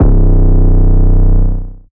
suv 808.wav